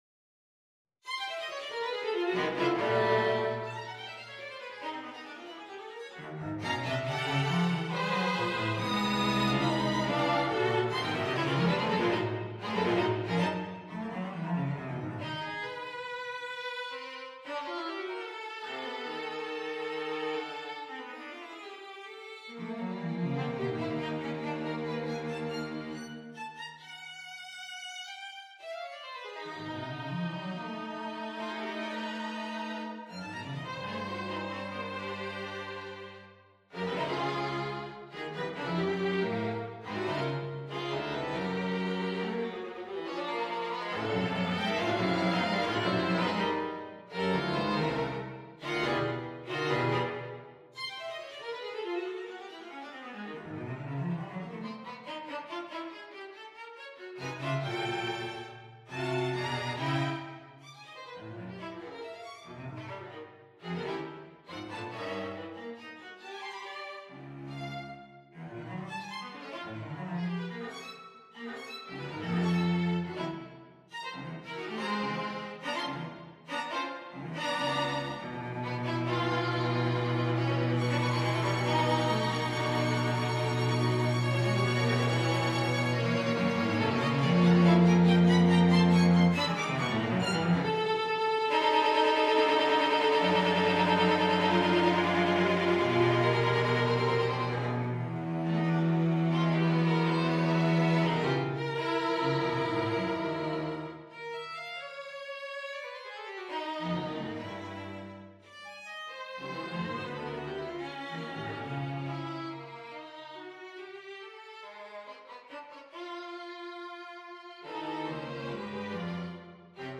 Quartet for Strings No.14 on a purpose-selected tone row Op.112 M�lhatatlan h�l�val Bart�k B�la Viktor J�nos �r�ks�g��rt �s p�ldamutat�s��rt 1. Allegro comodo 2. Lento molto 3. Scherzando. Allegro ma non troppo - Doppio più lento - A tempo 4. Largo appassionato 5. Allegro contrario